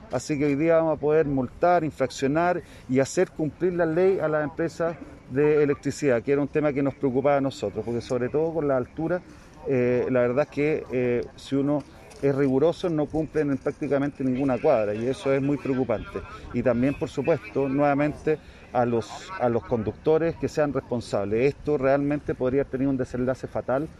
ds-alcalde.mp3